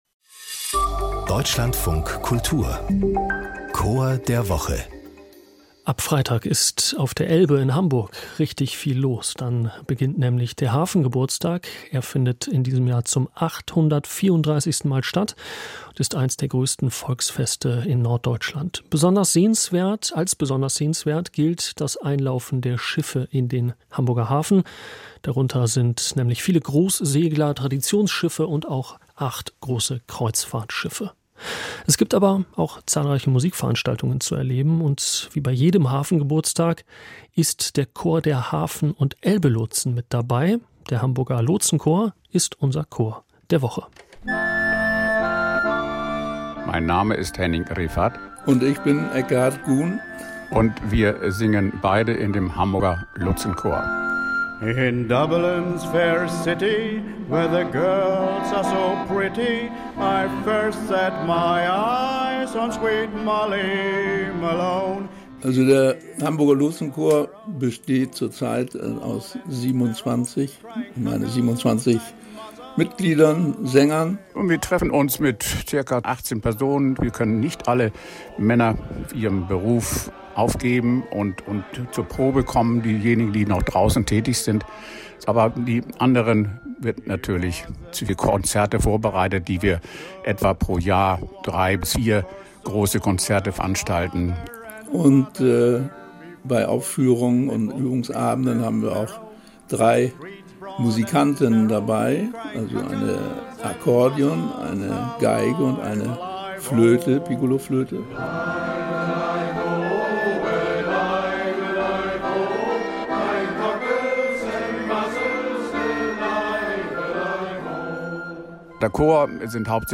Hamburger Lotsenchor